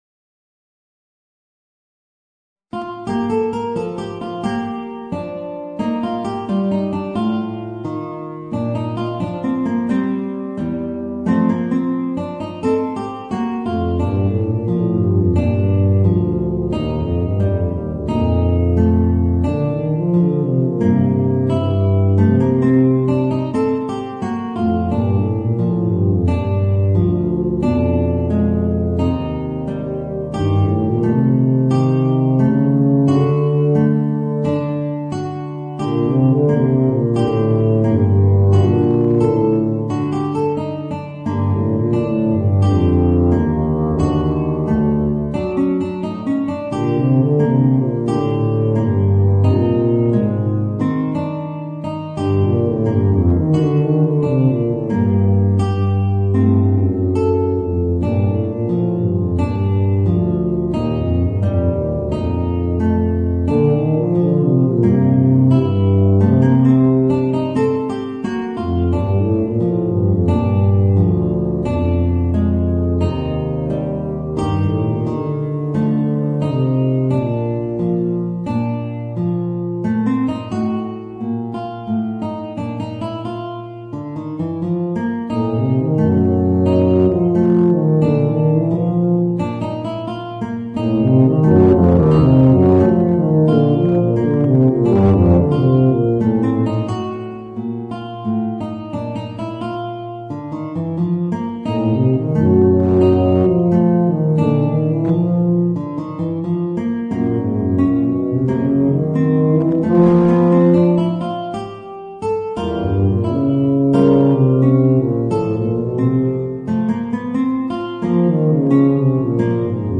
Voicing: Guitar and Bb Bass